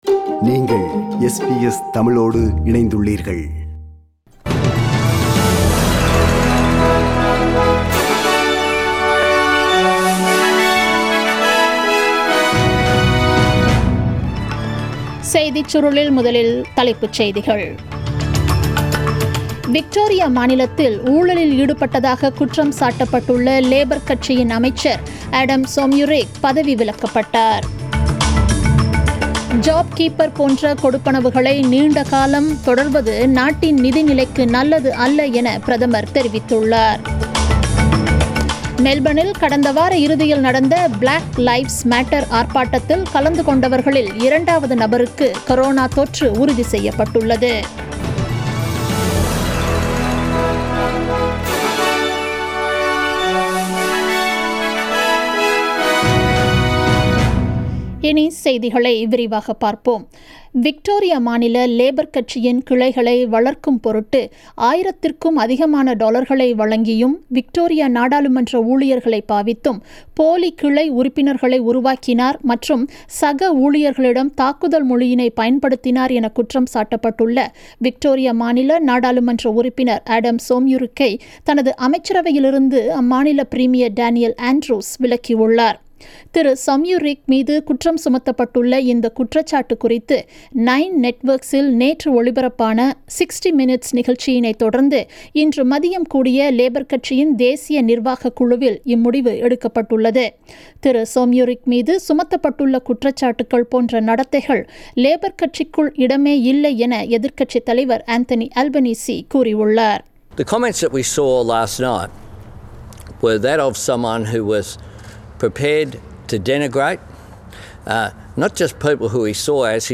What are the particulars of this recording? The news bulletin aired on 15th June 2020 at 8pm